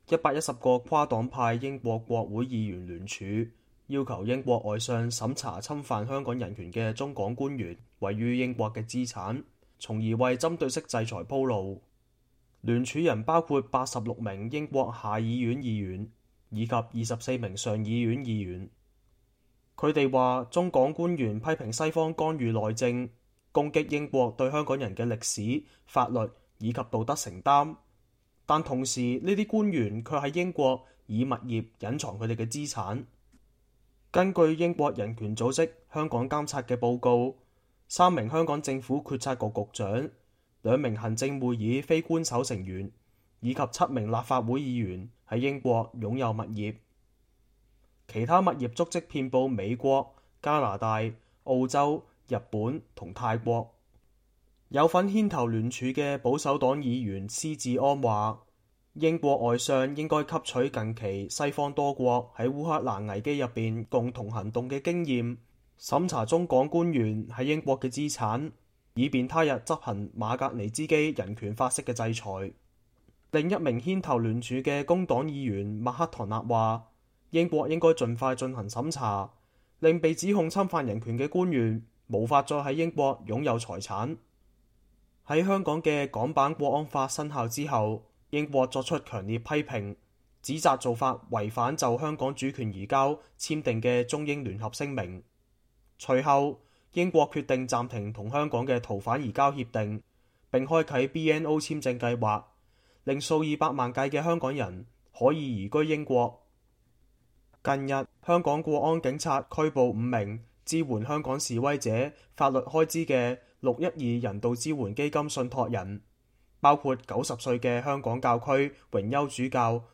倫敦 —